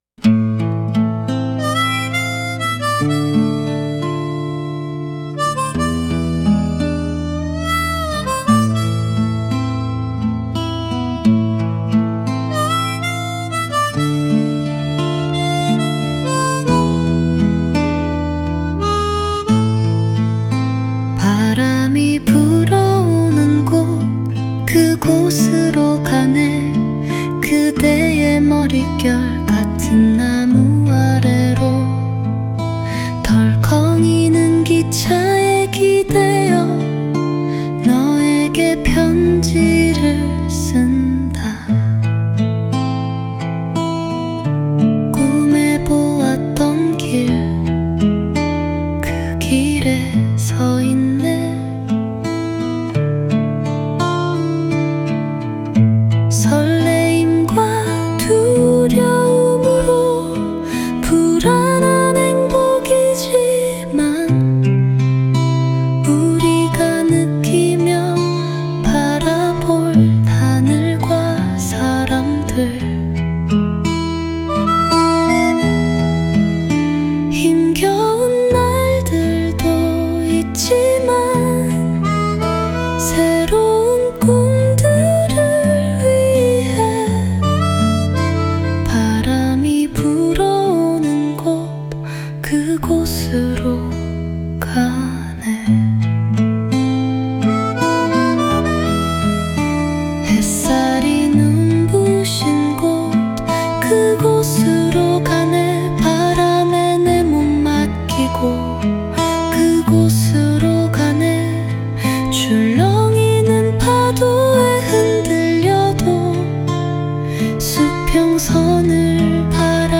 female version
sounds a bit like Karina to me